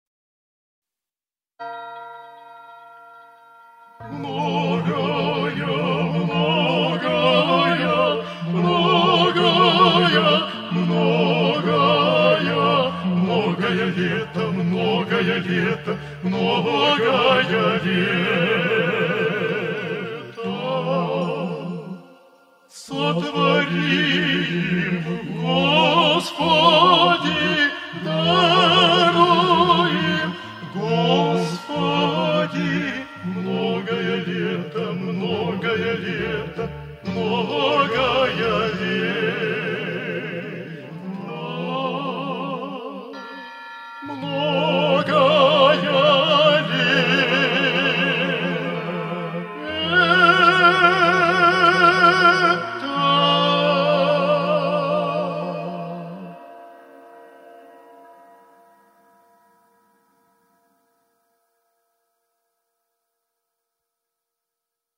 епископа Питирима
и народной артистки Республики Коми